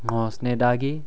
3. Intervocalic Voicing
All stops and affricates are voiced between vowels.
Example: /ŋ!ɔ̤snetak + i/ --> [
ŋ!ɔ̤snedagi] 'the community'